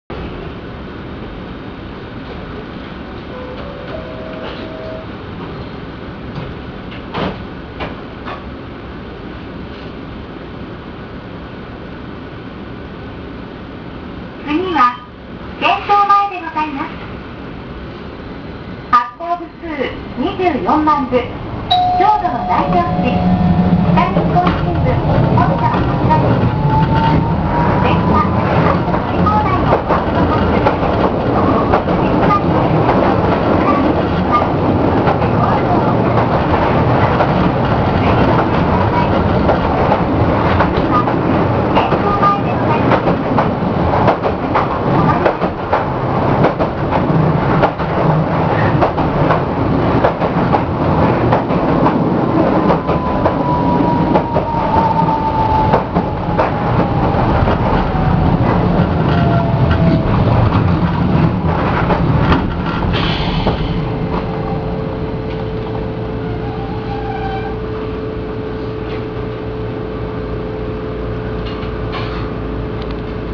・T100形走行音
【市内軌道線】新富町〜県庁前（1分13秒：399KB）
モーター音はあまり目立ちません。最初に聞こえる音階はドアチャイム。他で聞いたことが無いチャイムを用いています。